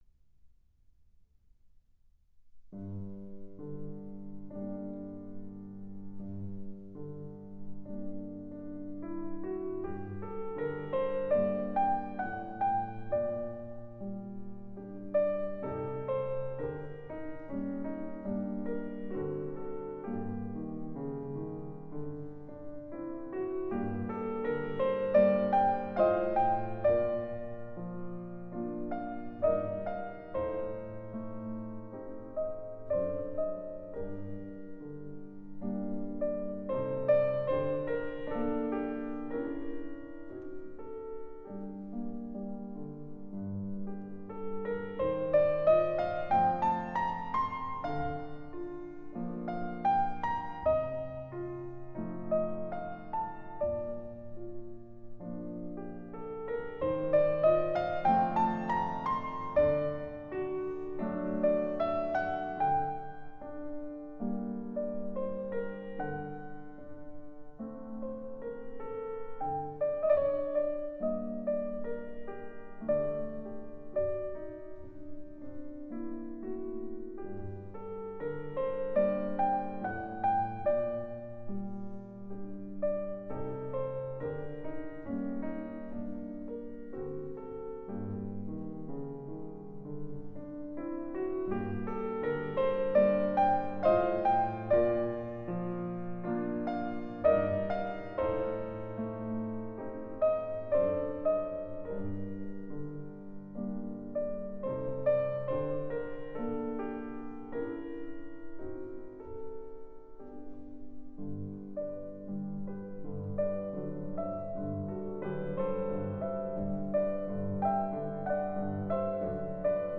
[原创]钢琴奏鸣曲《四季》柴可夫斯基